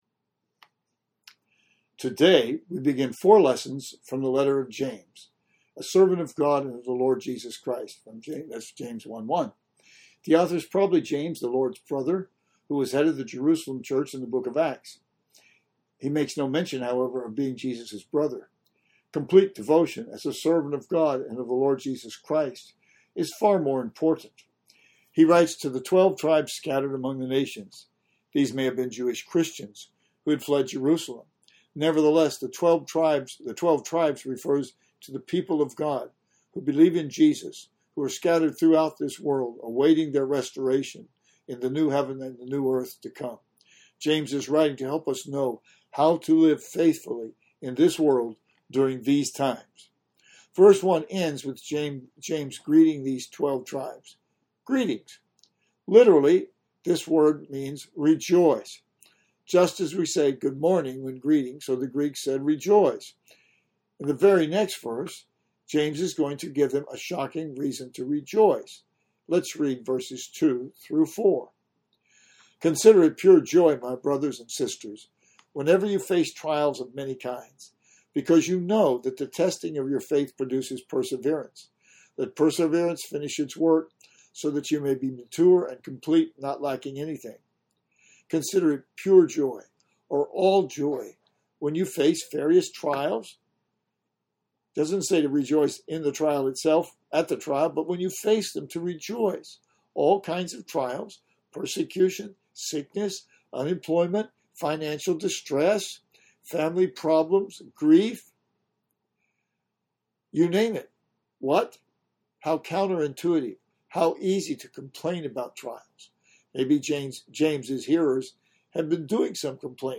Things of Time and Things of Eternity. Sunday School, Aug 2, 2020.